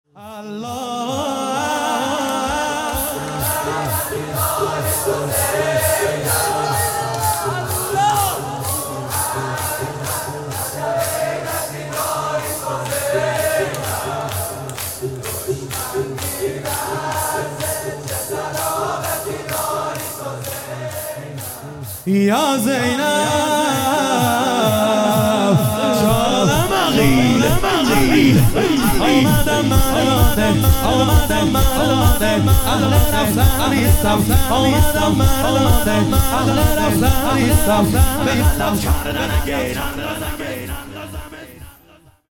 متن شور